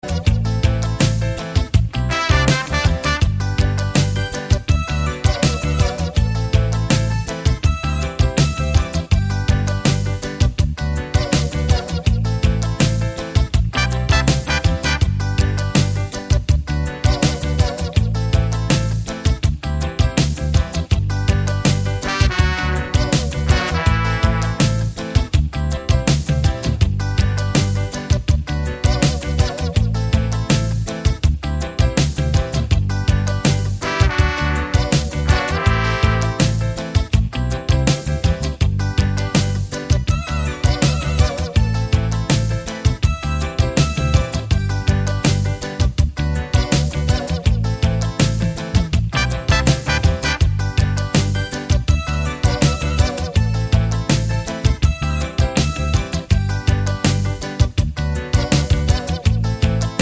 no Backing Vocals Reggae 3:44 Buy £1.50